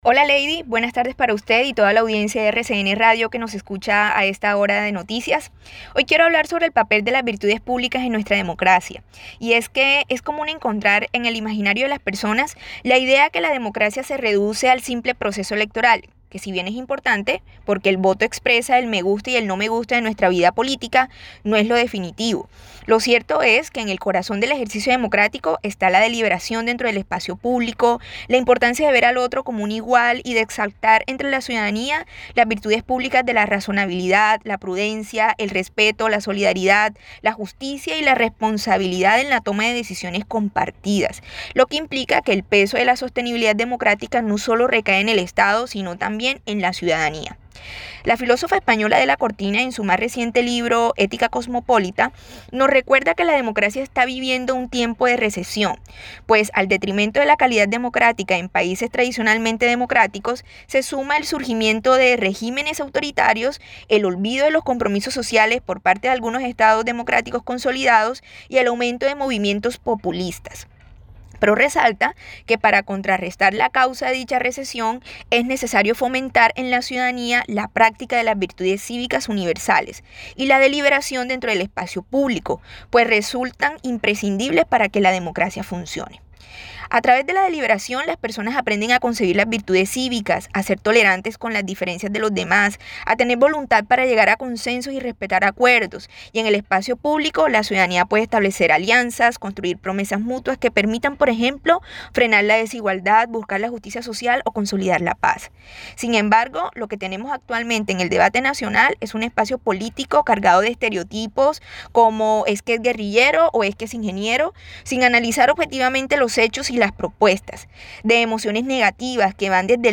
Esta columna se presentó en RCN Radio Cartagena.